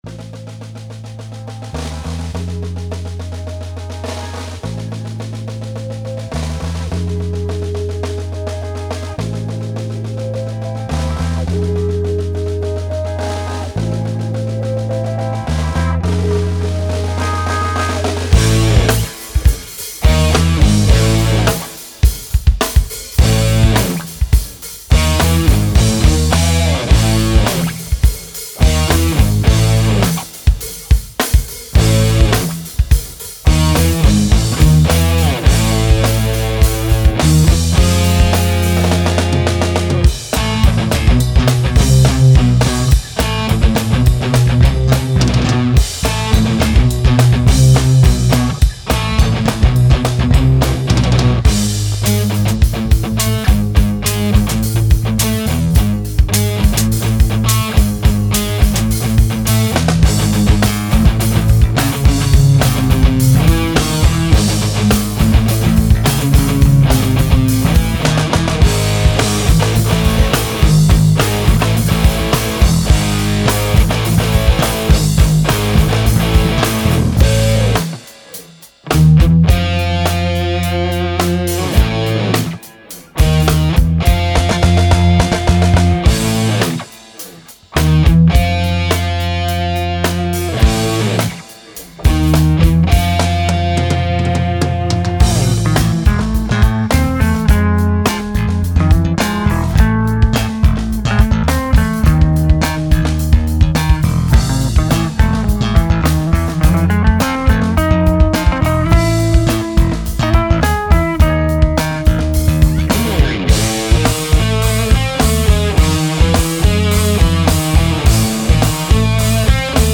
[DEMO] потому, что это записывалось не с целью выпустить прям студийный продукт, скорее, это демонстрация того, как мы, в принципе, можем это сыграть живьём - тут никаких прописанных миди партий, никаких сэмплов.